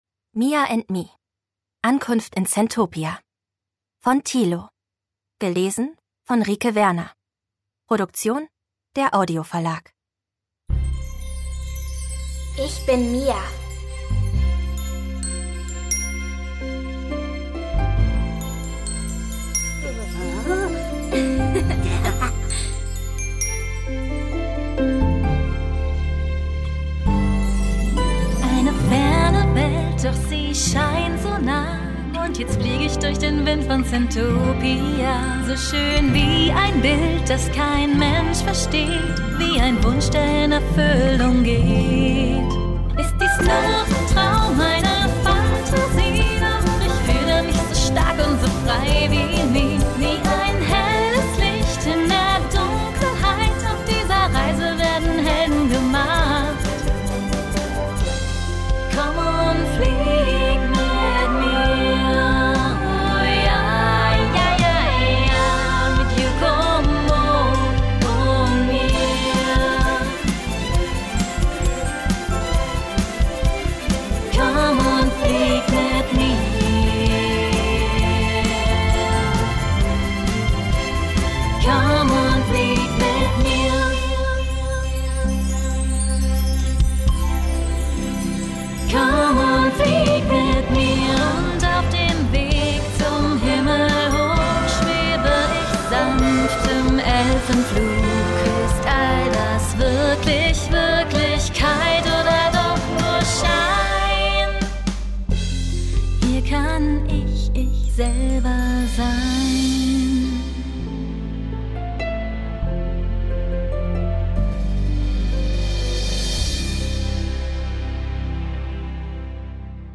Mia and me: Ankunft in Centopia – Das Hörbuch zur 1. Staffel Ungekürzte Lesung mit Musik